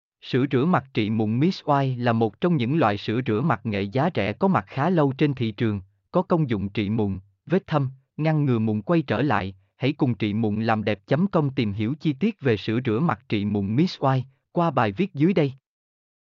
mp3-output-ttsfreedotcom-18-1.mp3